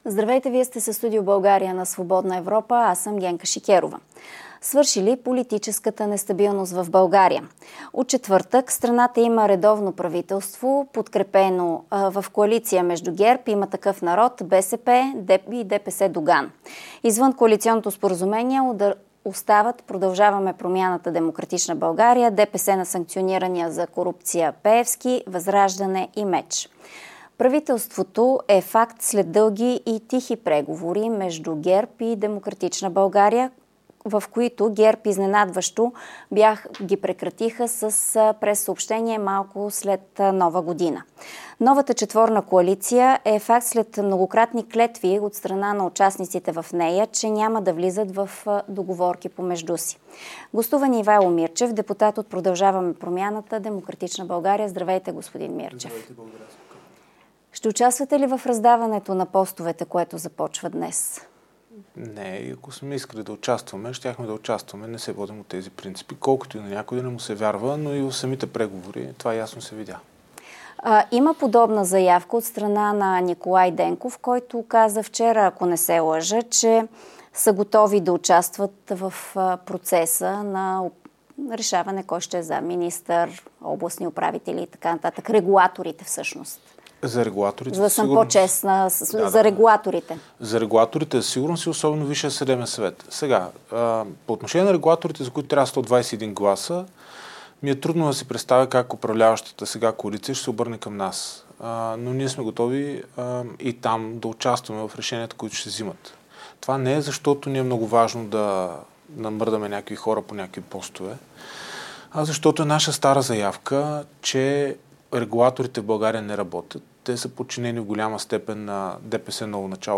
Депутатът от формацията Ивайло Мирчев гостува в Студио България с Генка Шикерова.